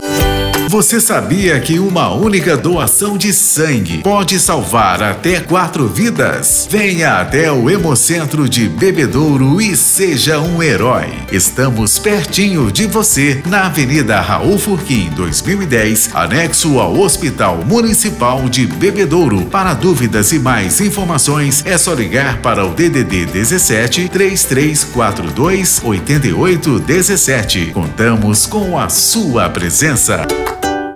Você também pode ajudar a divulgar a doação de sangue usando nossos spots para rádio ou carros de som: